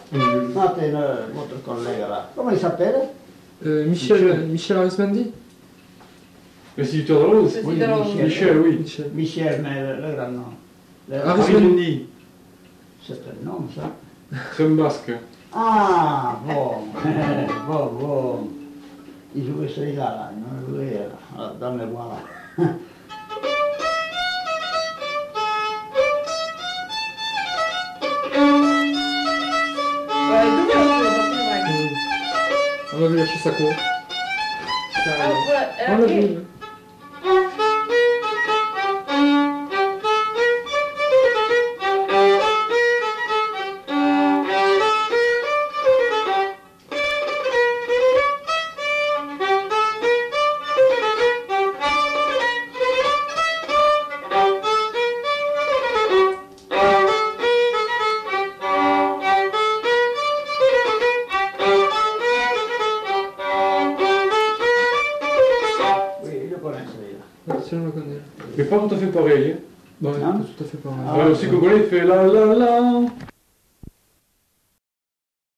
Lieu : Saint-Michel-de-Castelnau
Genre : morceau instrumental
Instrument de musique : violon
Danse : congo